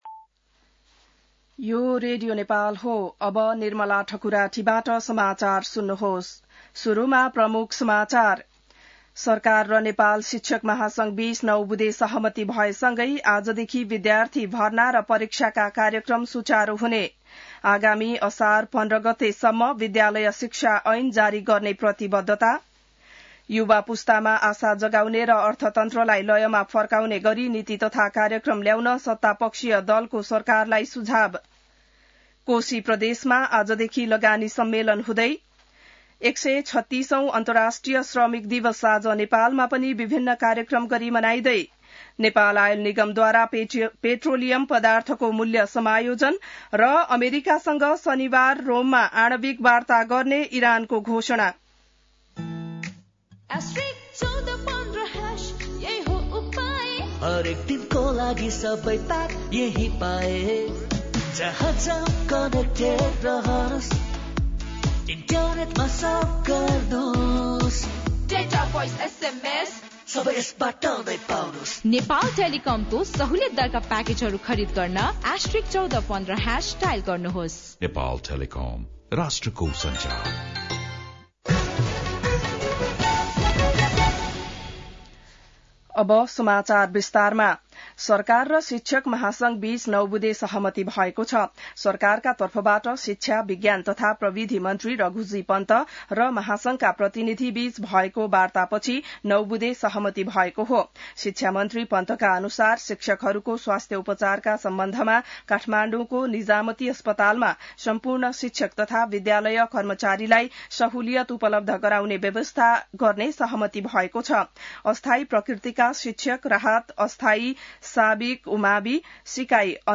An online outlet of Nepal's national radio broadcaster
बिहान ७ बजेको नेपाली समाचार : १८ वैशाख , २०८२